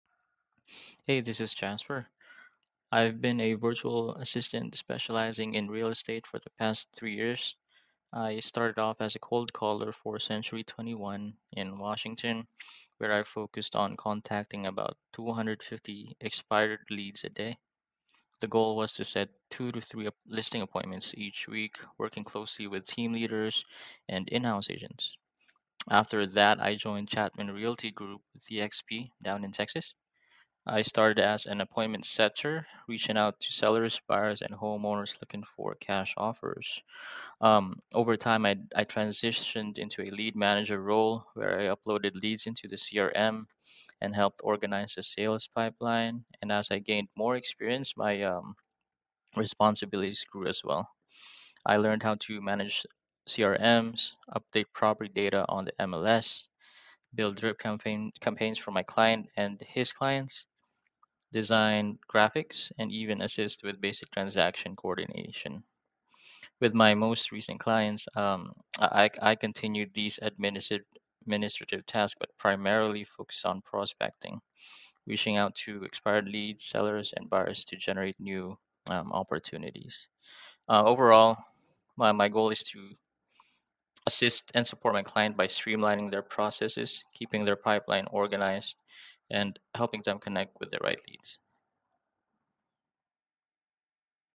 Self Introduction
Self-Introduction.mp3